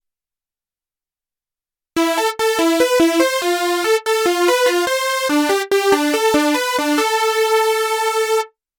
Schon mit den Standardeinstellungen des Nord Lead 4 hörst Du, was eine Hüllkurve ausmacht – beim Anschlag ist der Tiefpassfilter noch »offen« (es wird nichts gefiltert), aber nach und nach schließt er sich in einer fließenden Bewegung (die Filterfrequenz wandert nach unten).
Für unseren Lead-Sound nutzen wir folgende Einstellungen:
• DECAY: 8.5
• SUSTAIN: 7.7
• RELEASE: 2.5
07__oscfilterenv.mp3